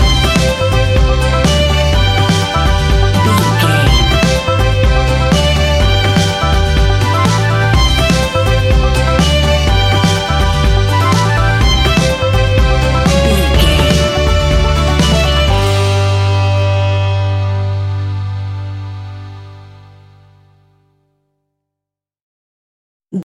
Uplifting
Ionian/Major
Fast
acoustic guitar
mandolin
double bass
accordion